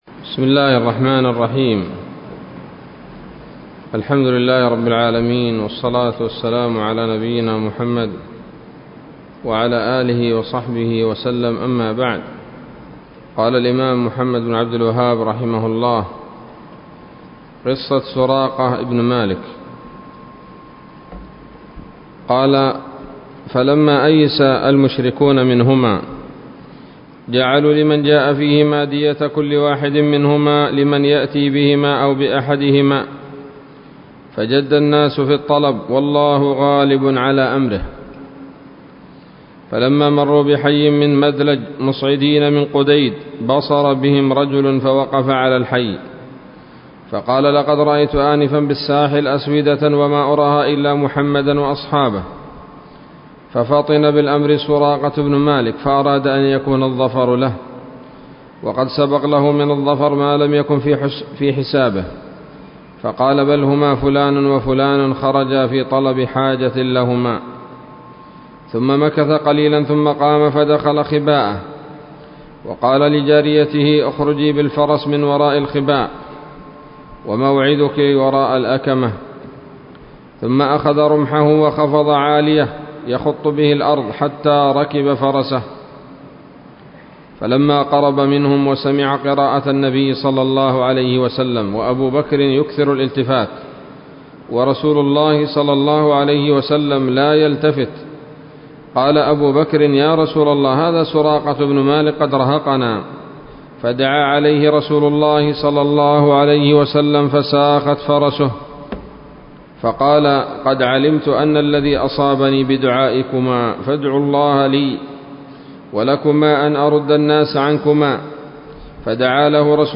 الدرس الثلاثون من مختصر سيرة الرسول ﷺ